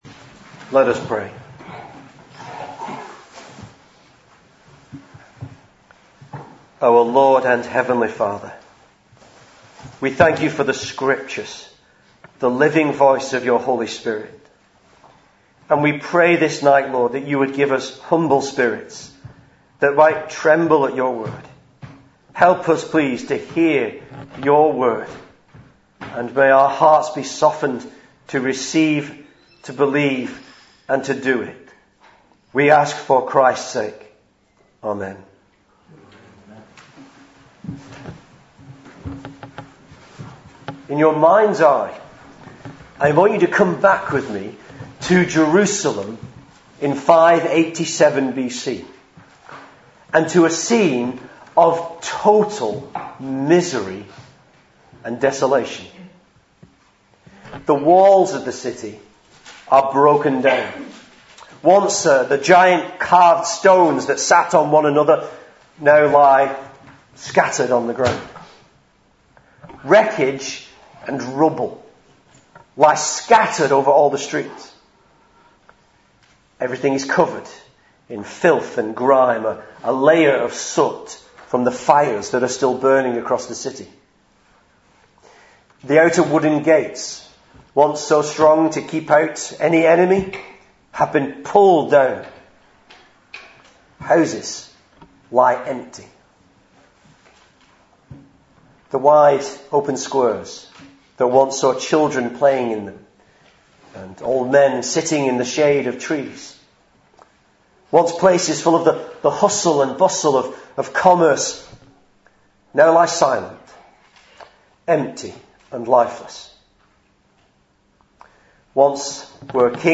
Series: Single Sermons